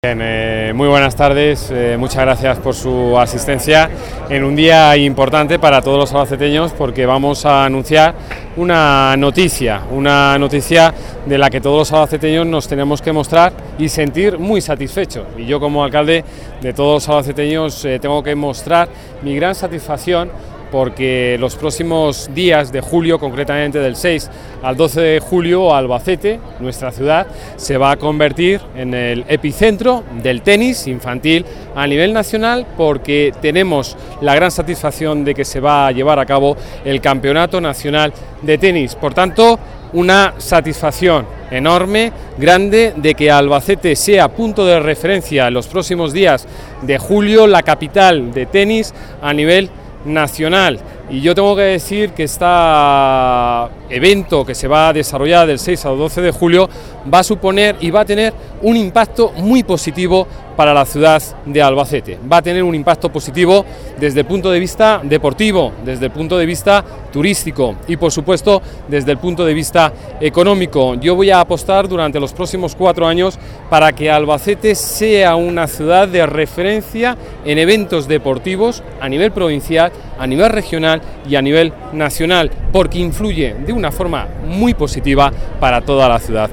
Esta tarde se ha presentado, en la Plaza del Altozano de Albacete, la Fase Final Nacional de Copa Coca-Cola de Tenis, el Campeonato de España Infantil de la Real Federación Española de Tenis, que se va a celebrar del 6 al 12 de julio de 2015 en el Club Tenis Albacete.
Corte-de-sonido-Javier-Cuenca-Albacete-será-el-epicentro-del-tenis-nacional.mp3